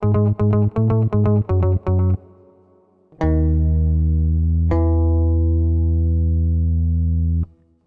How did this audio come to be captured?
Also below in a faux Ableton-style interface are all the original loops used to create the tracks.